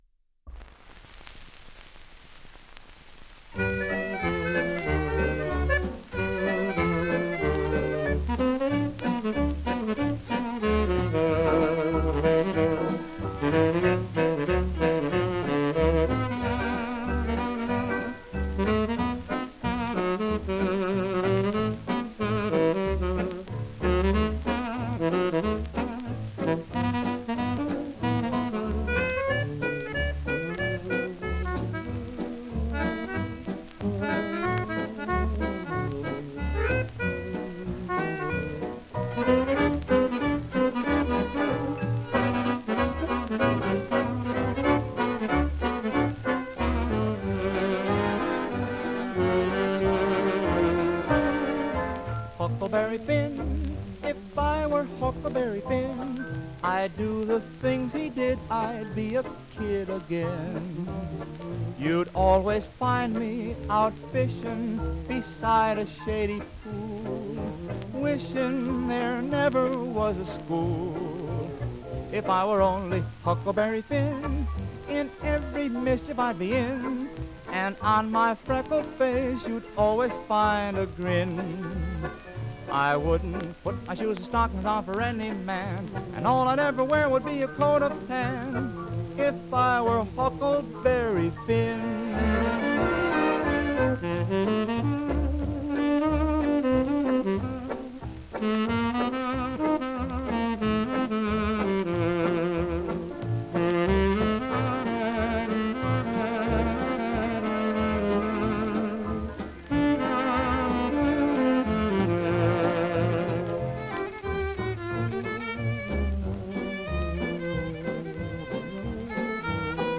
Genre: Fox-Trot.